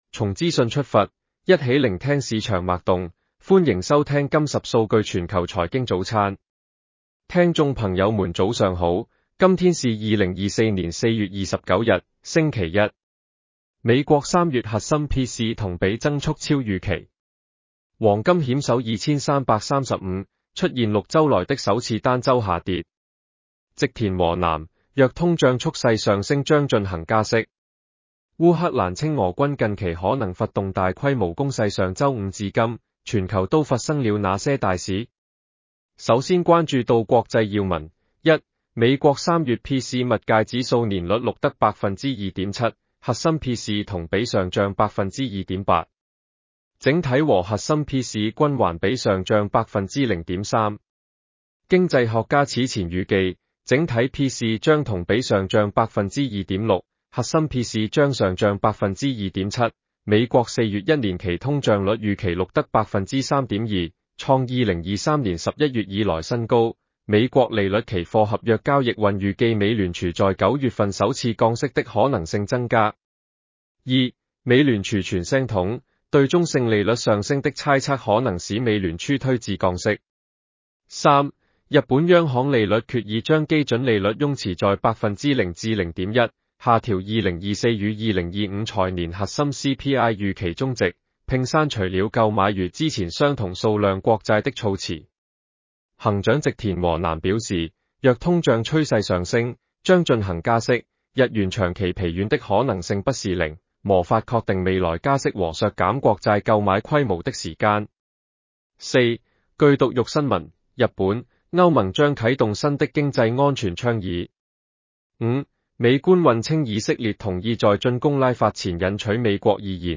男生普通话版